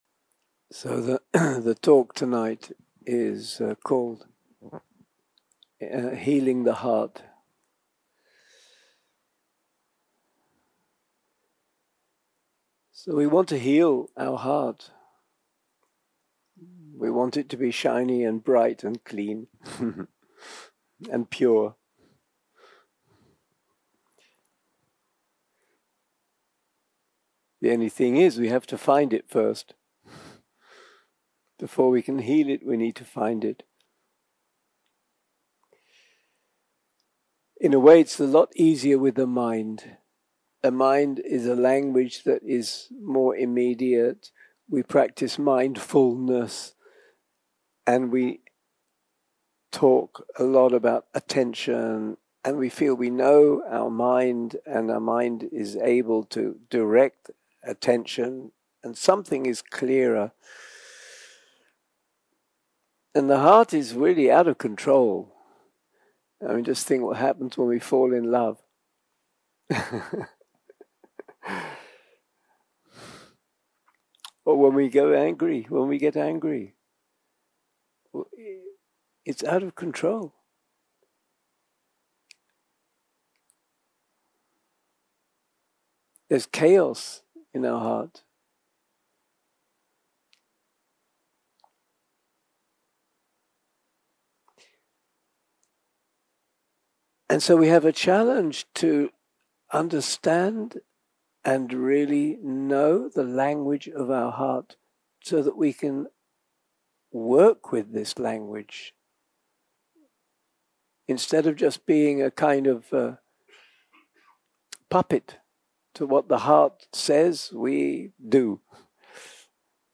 יום 3 - הקלטה 6 - ערב - שיחת דהרמה - Healing the Heart
Dharma type: Dharma Talks שפת ההקלטה